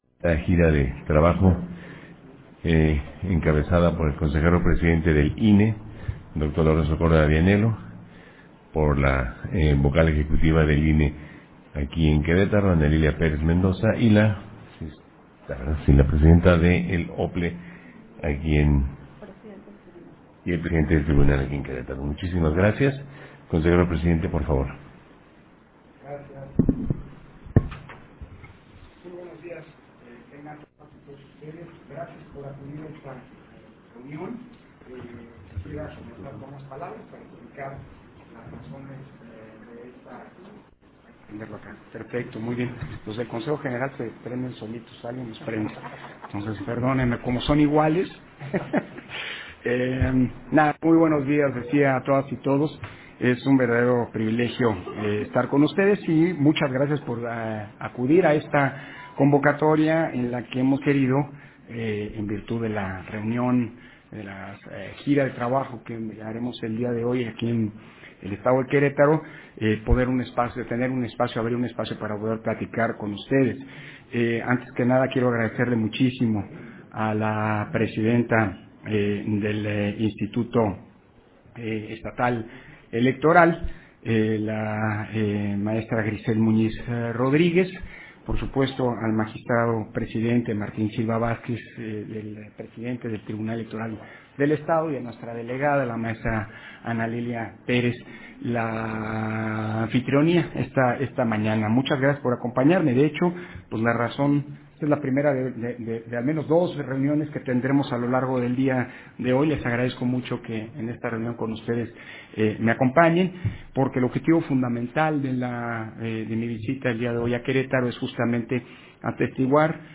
Versión estenográfica de la Conferencia de Prensa que ofreció Lorenzo Córdova, en su visita de trabajo a Querétaro